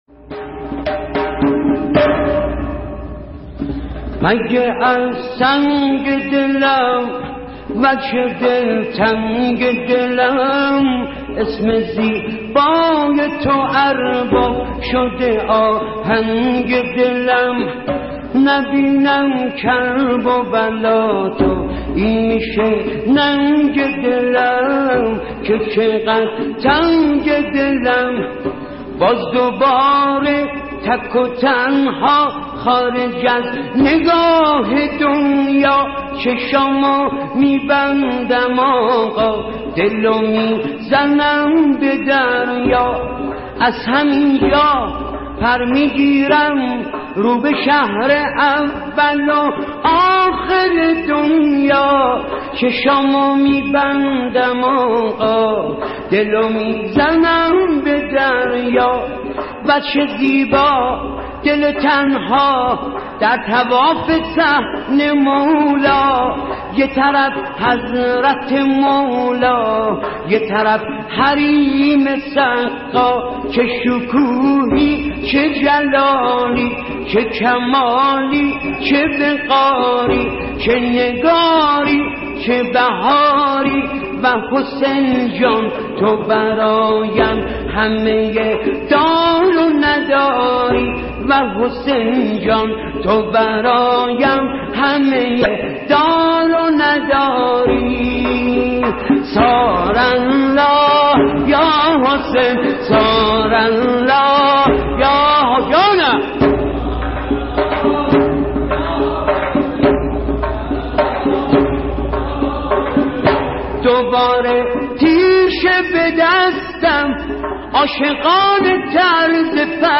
نوای زورخانه ای